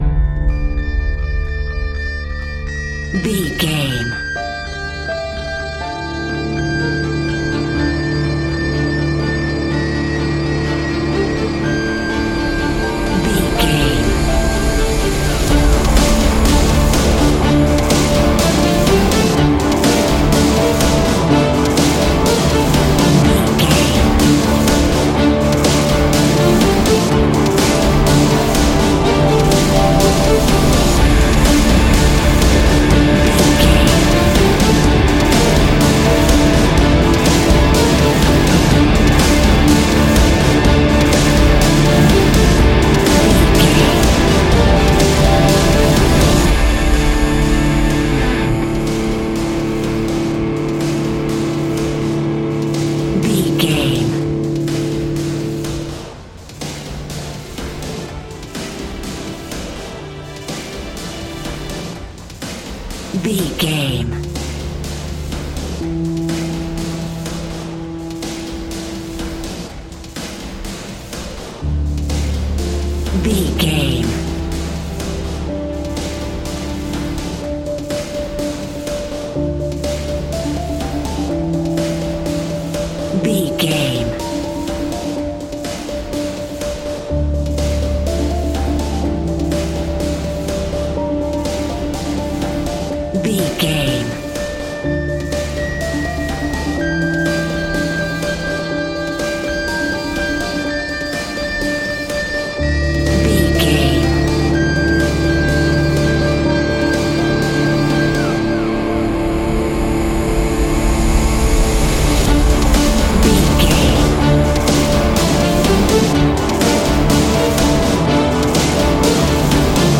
Ionian/Major
D
angry
aggressive
electric guitar
drums
bass guitar